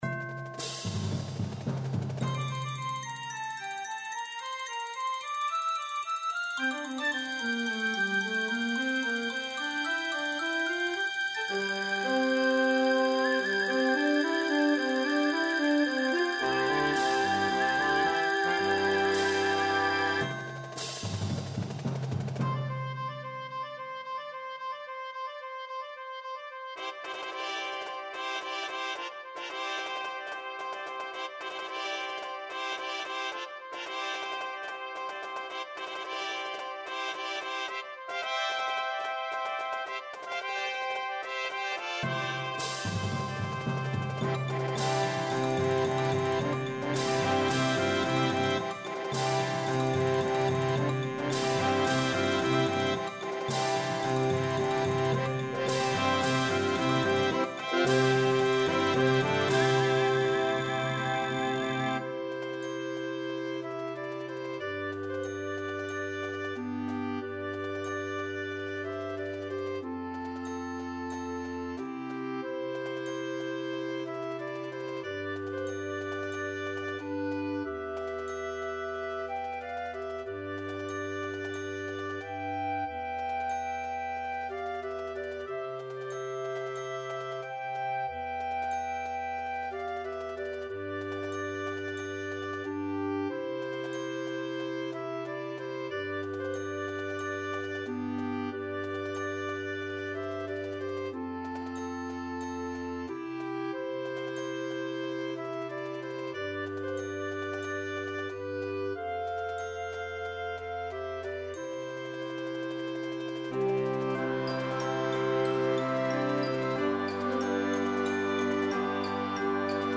is an energetic and exciting opener.